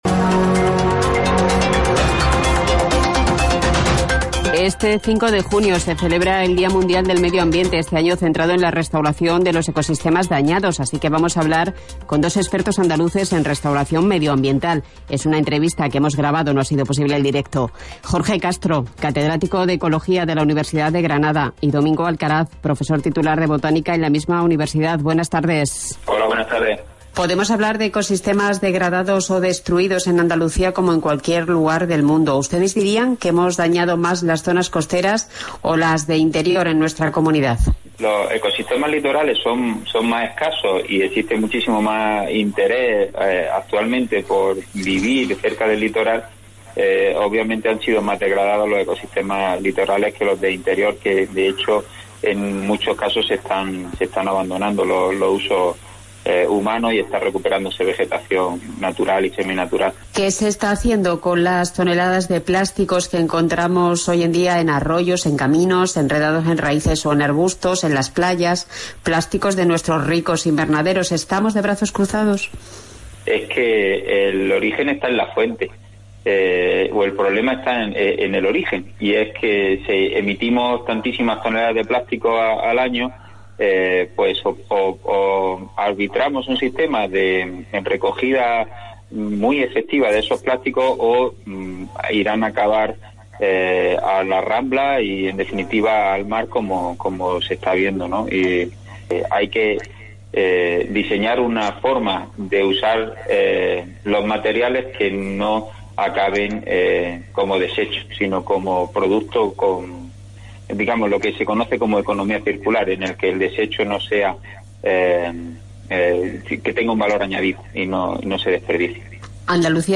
entrevista a los investigadores de la Universidad de Granada